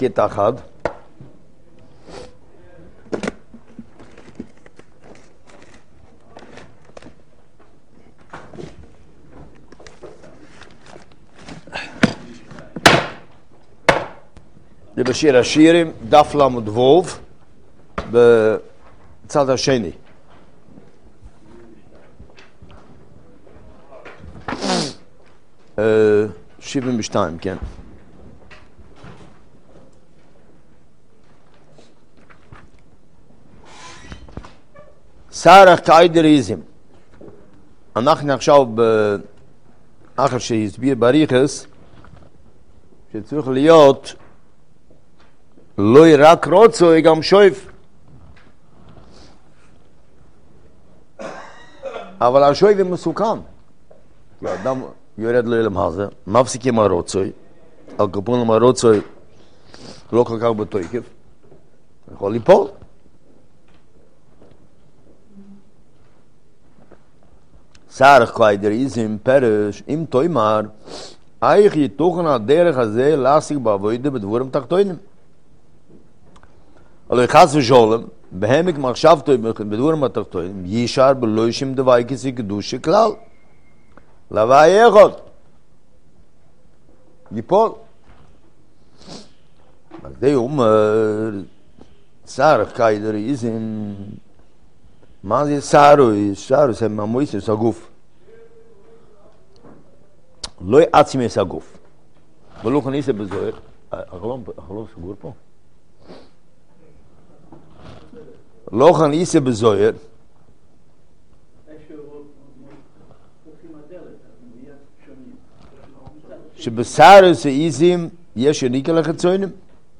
שיעור יומי